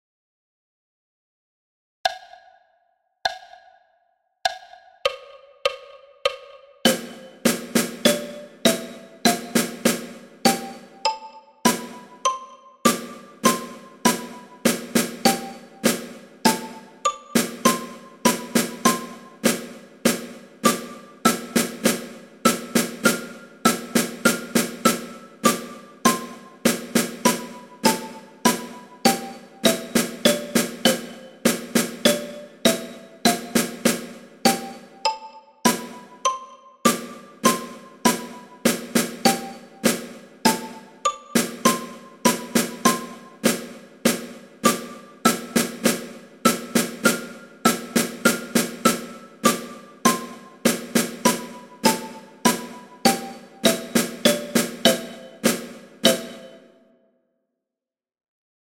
Pas à pas – xylo et caisse claire à 100 bpm
Pas-a-pas-xylo-et-caisse-claire-a-100-bpm.mp3